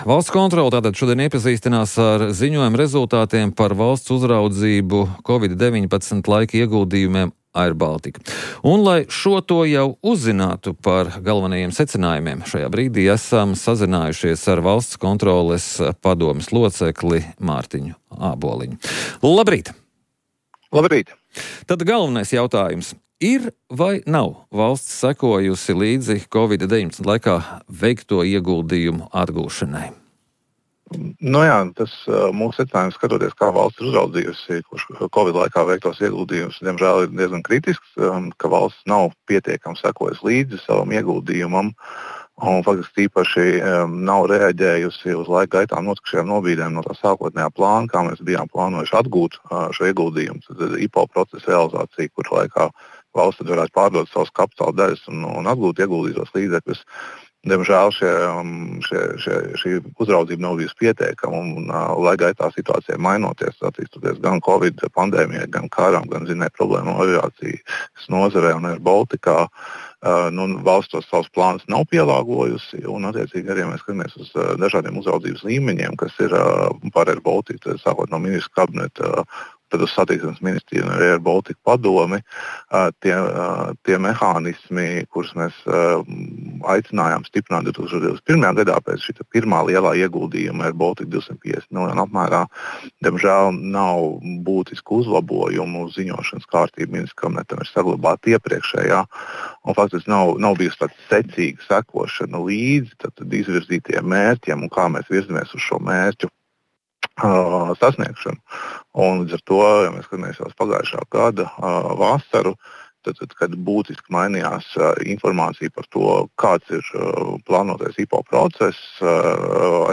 Igors Rajevs: Miera sarunu virzībā Ukrainas karā ir ieinteresētas vien ASV – Rīta intervija – Podcast